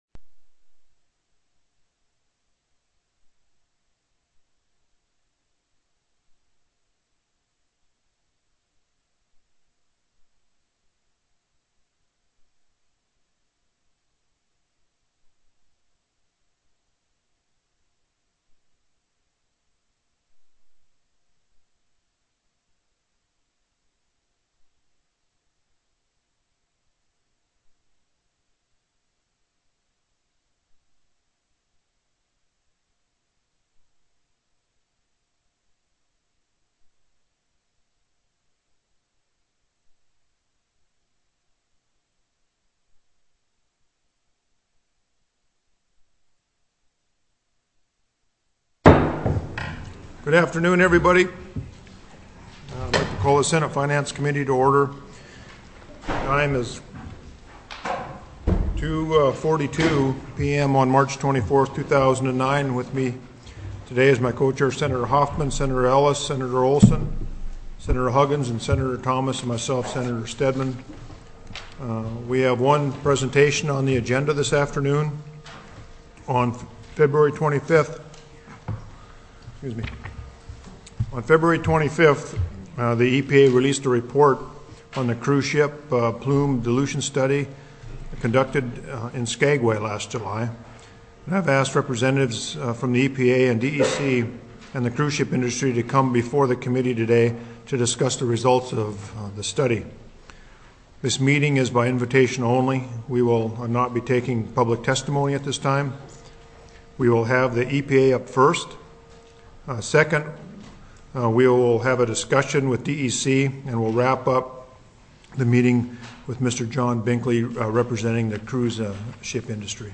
03/24/2009 01:30 PM Senate FINANCE